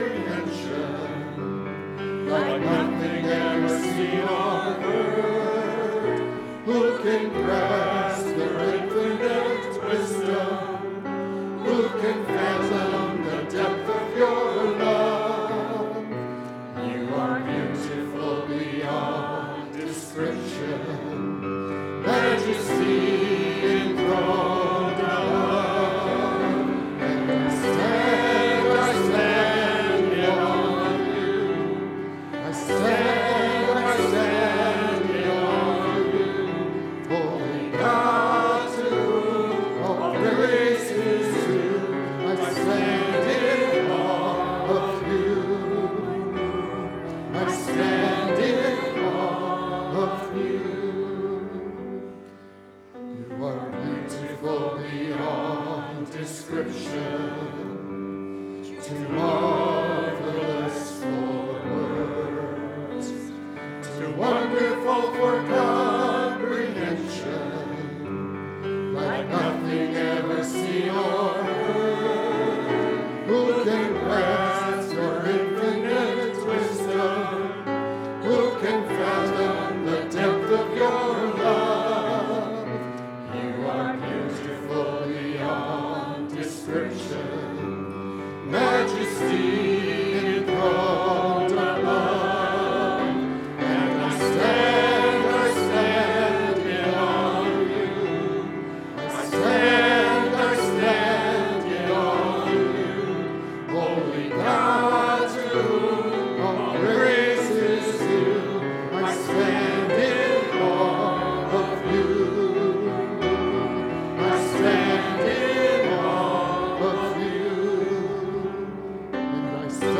Passage: I Thessalonians 2:10-13 Download Files Notes Bulletin Topics: Confirmation Sunday « “Friends